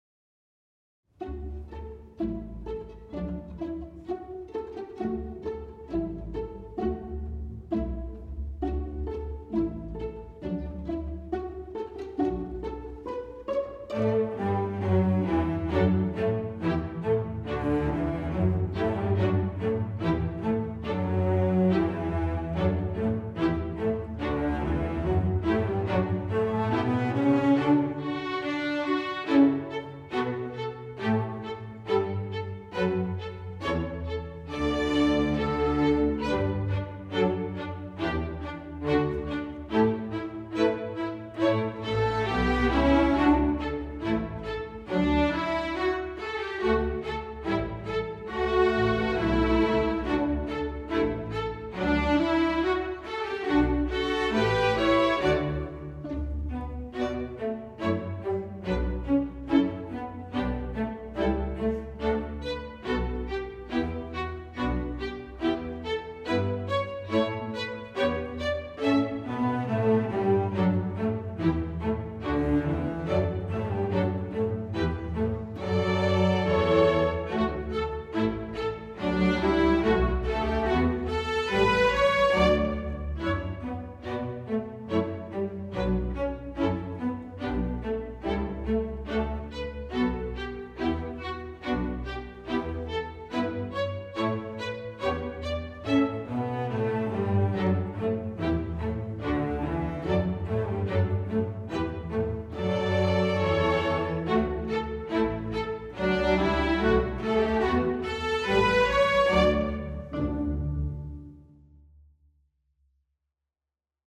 Cleverly scored with fulfilling contrapuntal writing
String Orchestra